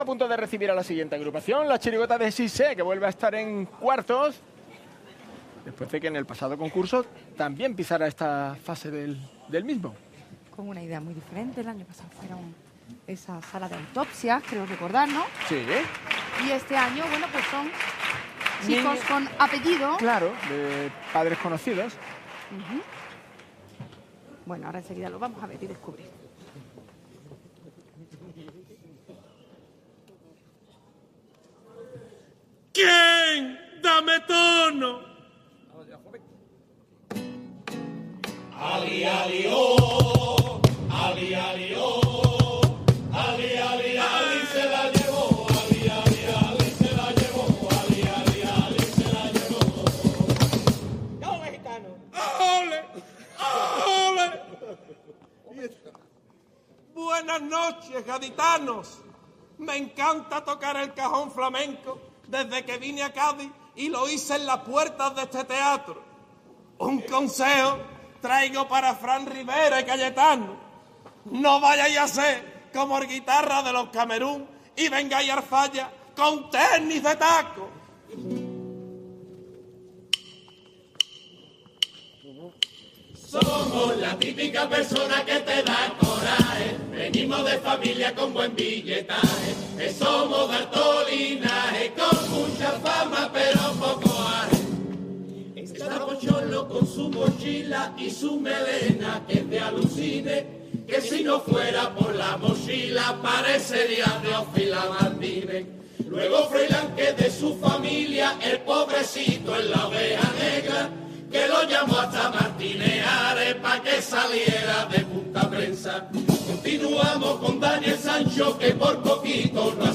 en la fase cuartos del COAC Carnaval de Cádiz 2026